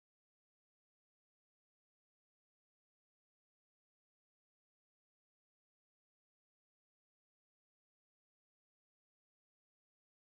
silencio
u2-sec1-silencio.mp3